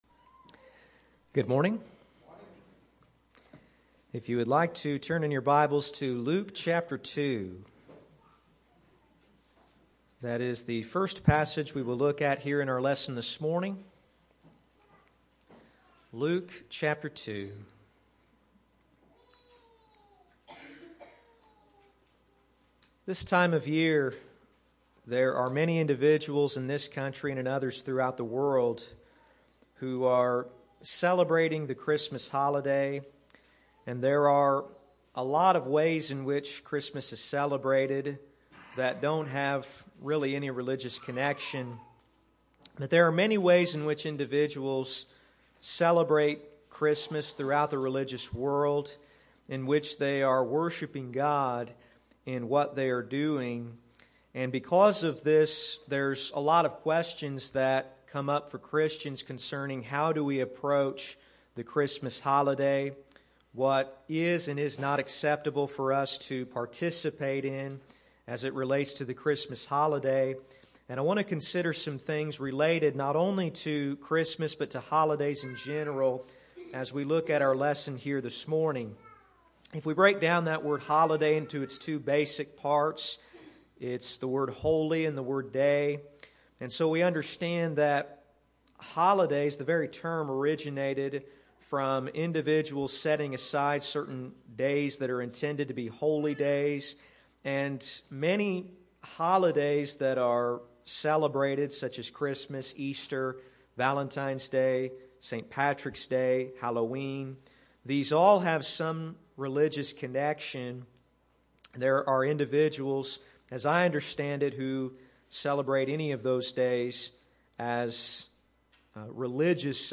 Sermons - Olney Church of Christ